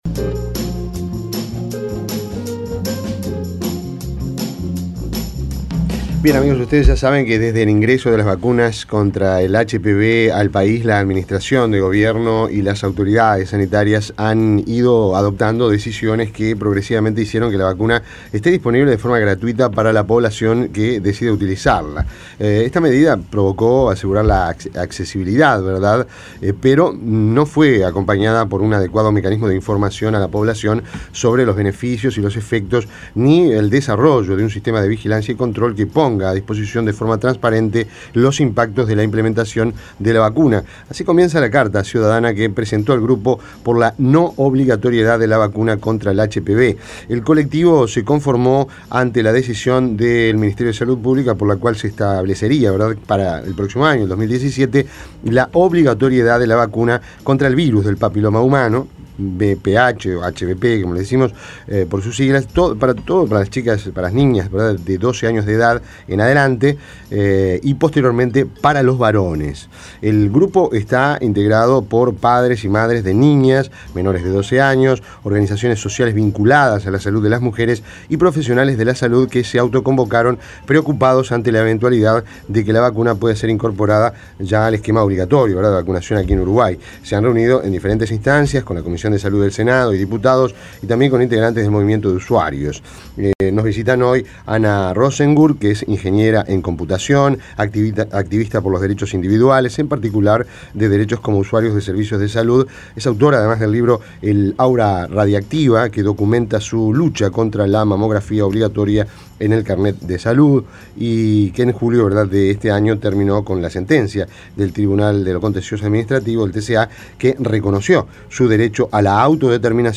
Entrevista en Rompkbzas VPH: Vacuna obligatoria, ¿sí o no?